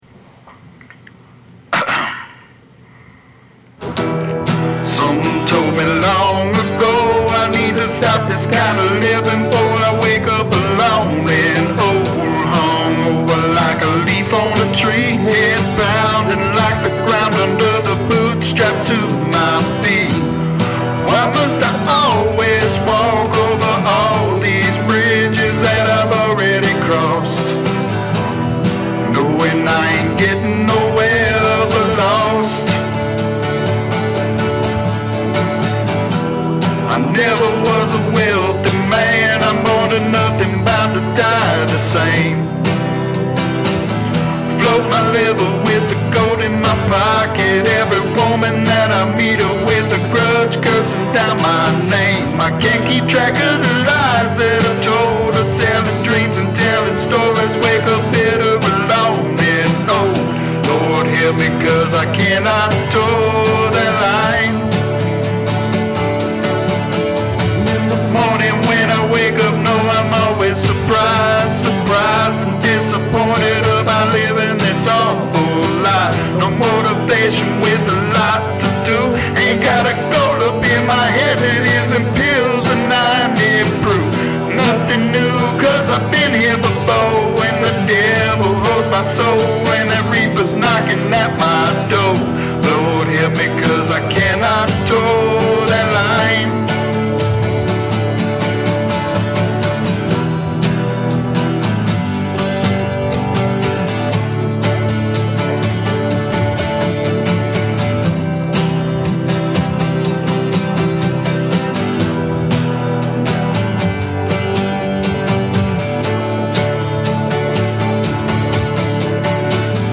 Raw-songs
Folk
Country-rock